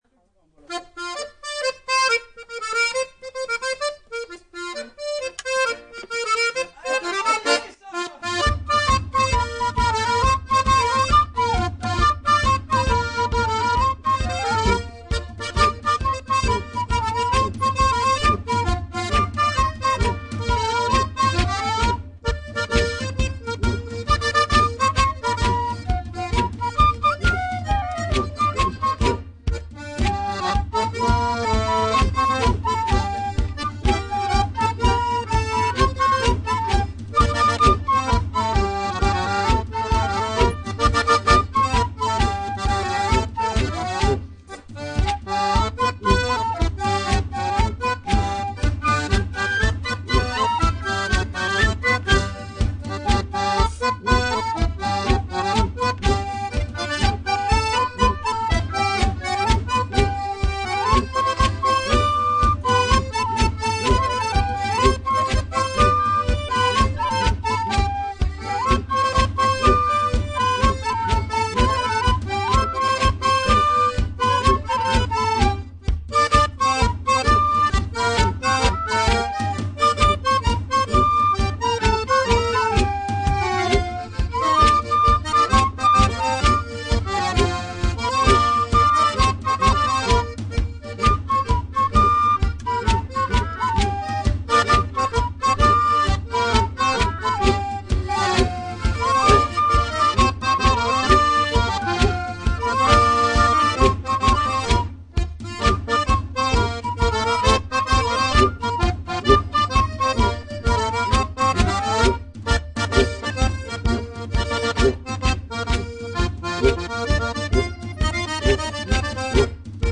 La musique traditionnelle Niçoise